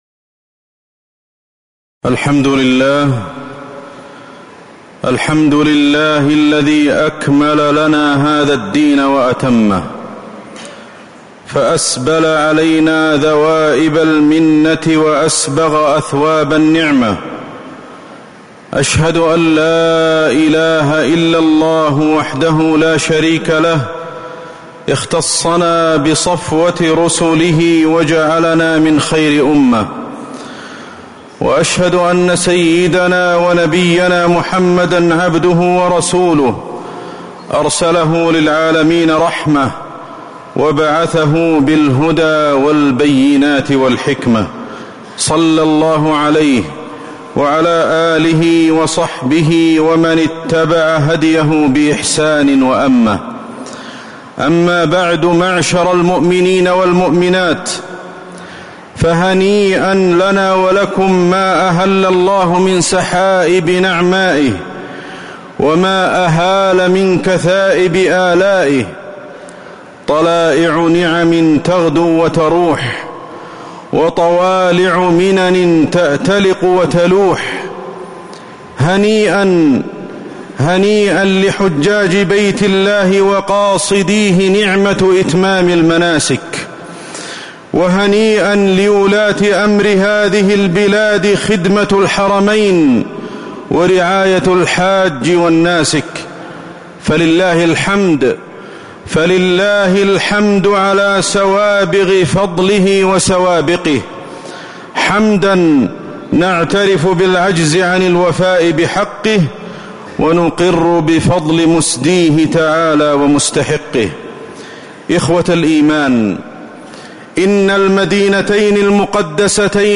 تاريخ النشر ٢٢ ذو الحجة ١٤٤٥ هـ المكان: المسجد النبوي الشيخ: فضيلة الشيخ أحمد بن علي الحذيفي فضيلة الشيخ أحمد بن علي الحذيفي وداع موسم الحج